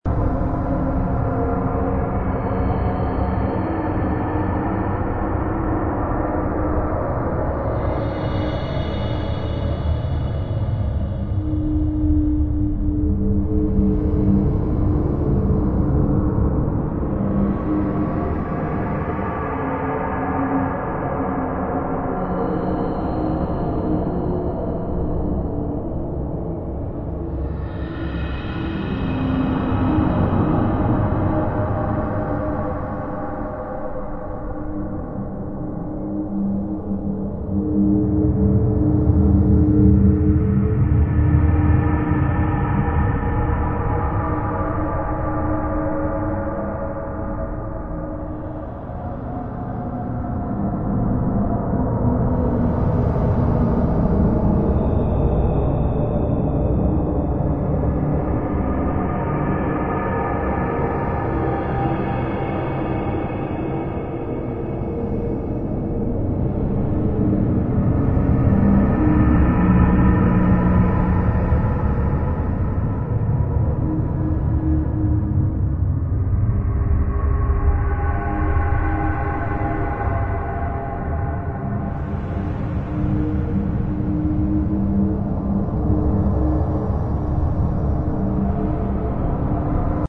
zone_field_ice.wav